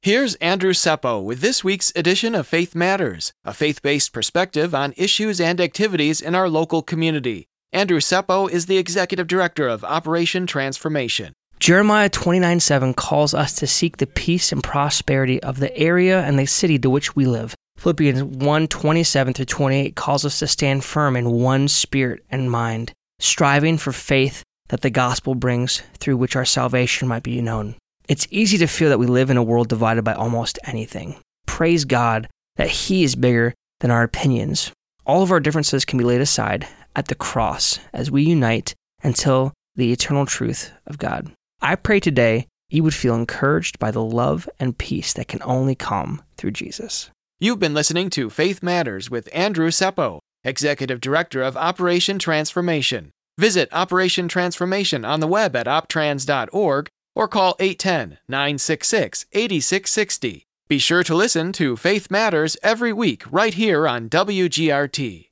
Faith Matters is a weekly radio feature that airs every Monday on WGRT 102.3 FM. Featuring information and commentary about spiritual issues - nationally and in your local community.